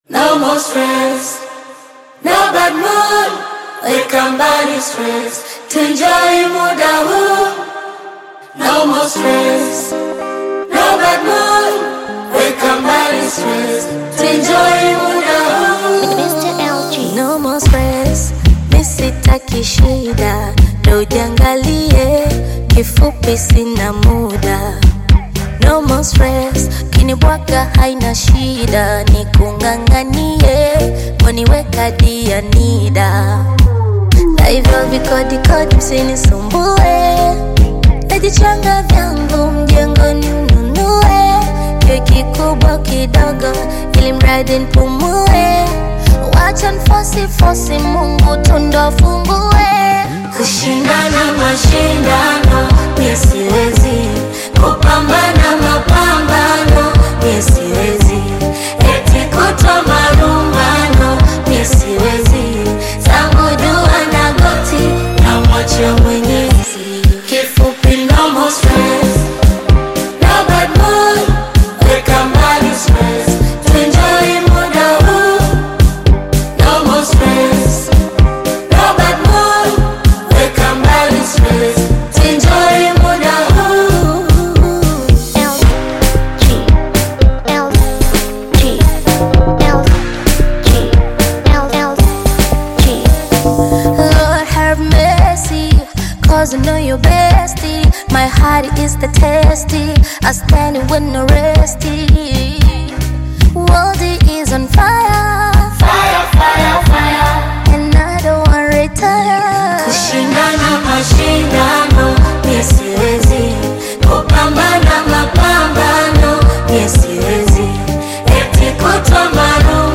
uplifting Afro-Pop/Bongo Flava single
soulful vocals
Genre: Amapiano